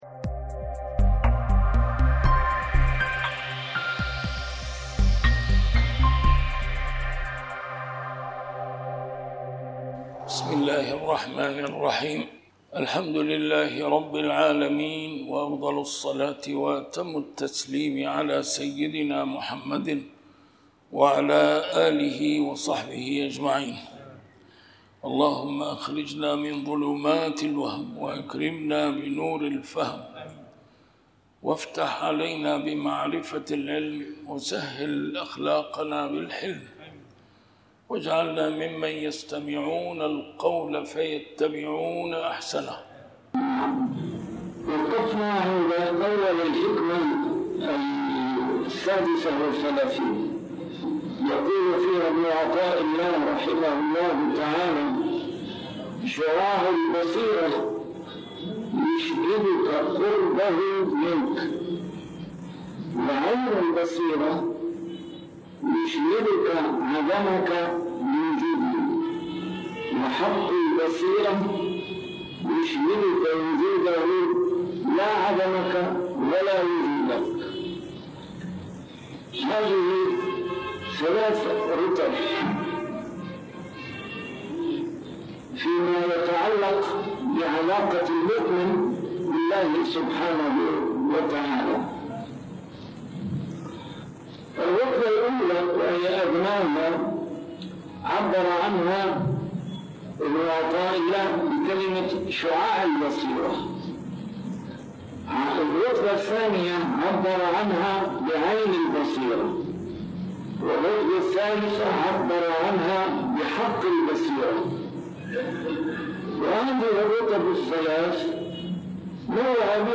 A MARTYR SCHOLAR: IMAM MUHAMMAD SAEED RAMADAN AL-BOUTI - الدروس العلمية - شرح الحكم العطائية - الدرس رقم 54 شرح الحكمة 36